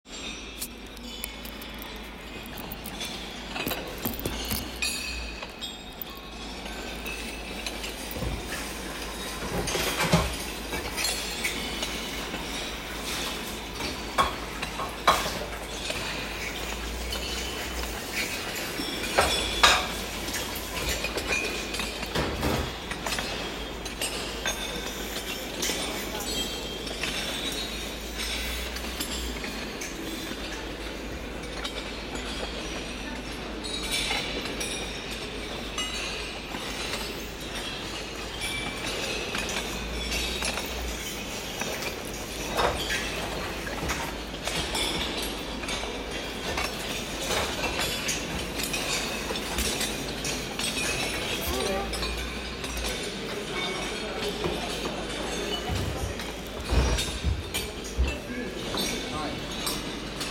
This “solitaire of sounds” ends with the sound of students walking through the “Jewish Memorial in Berlin” – footsteps echoing between stone and concrete, like the echoes of history, and as a testimony of repression. The sound of footsteps echoing between the stone and concrete is like the echo of history, and also like a depressing testimony.